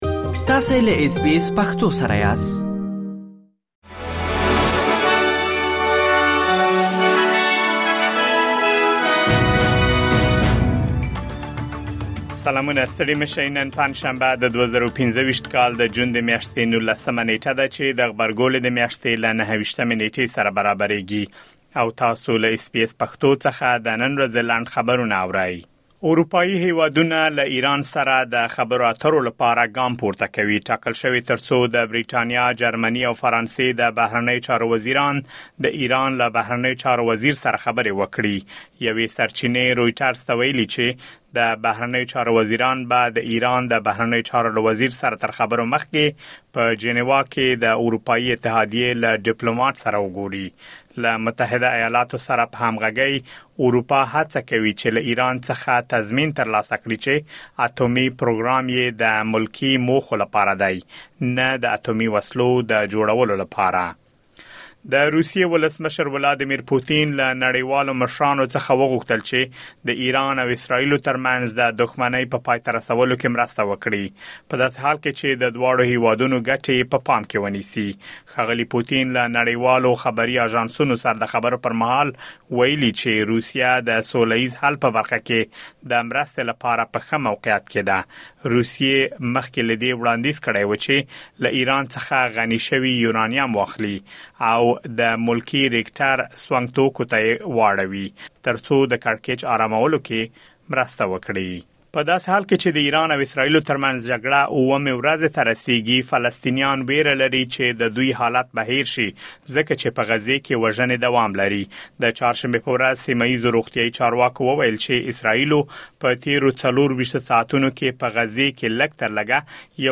د اس بي اس پښتو د نن ورځې لنډ خبرونه | ۱۹ جون ۲۰۲۵